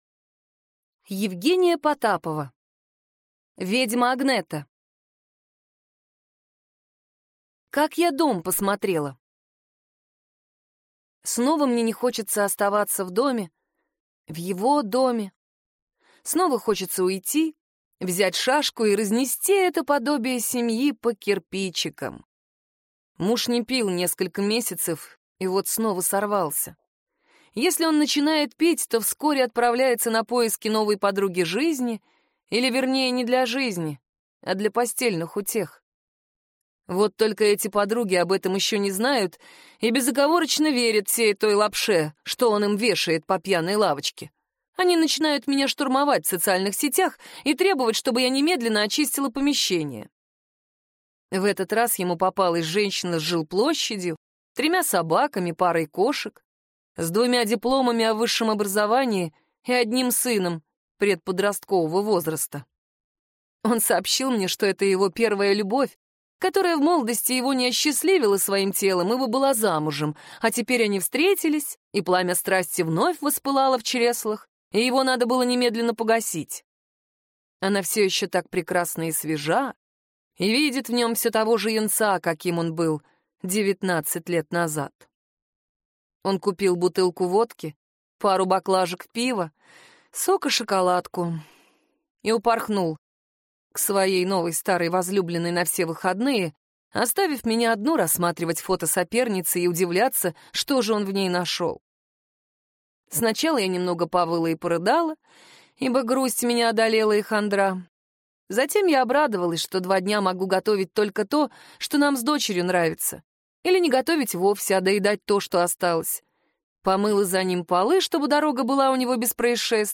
Аудиокнига Ведьма Агнета | Библиотека аудиокниг